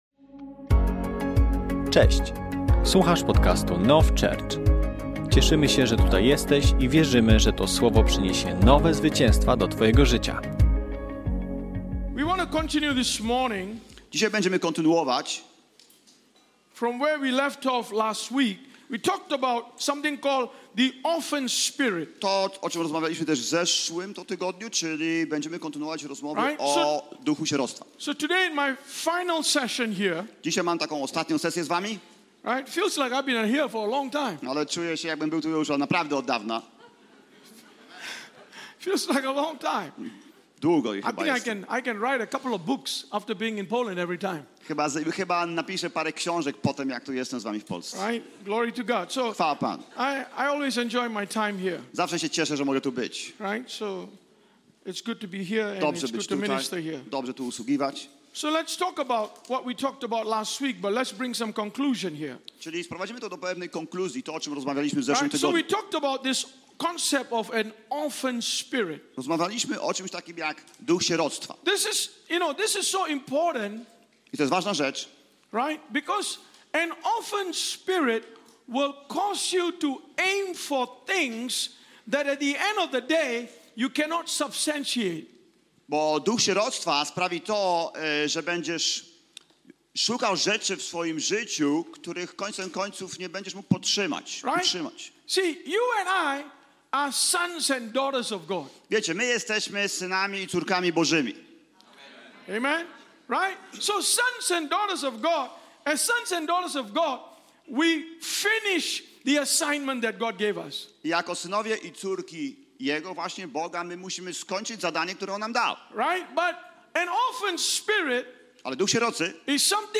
nauczanie